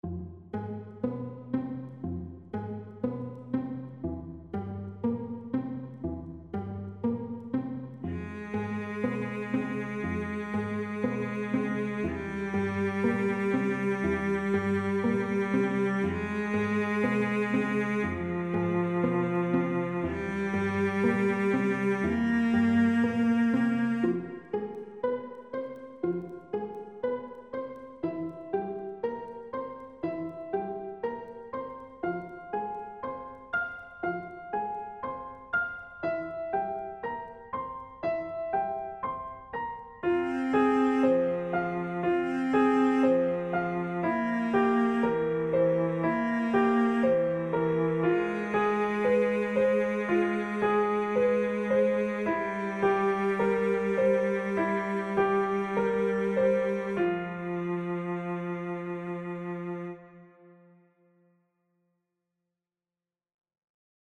Tags: game show sounds